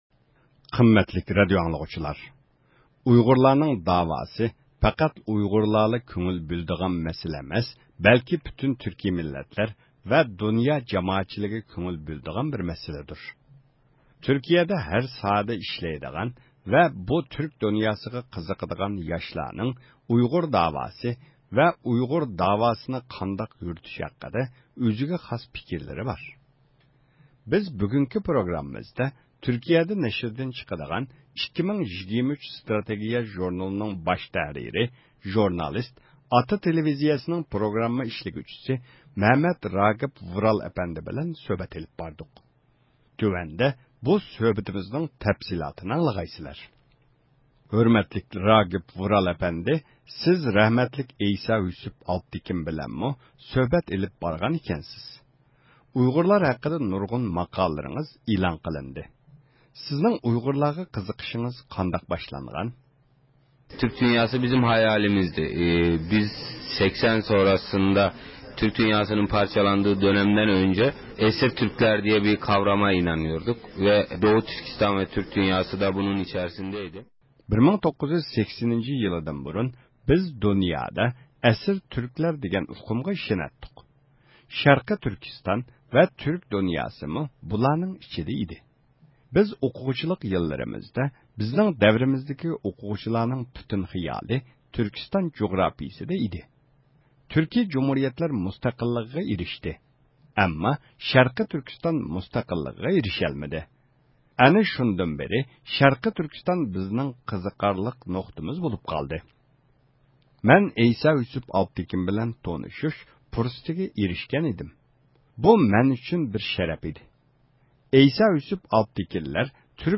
ئۇيغۇر مەسىلىسى ھەققىدە سۆھبەت